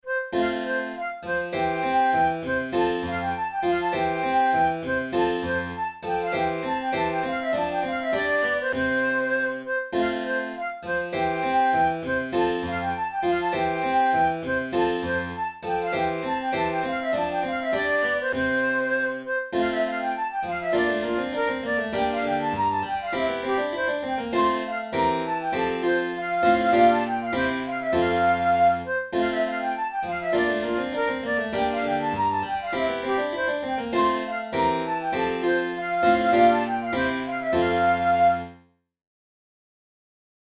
from Violin Sonata in F major, transcribed for flute and keyboard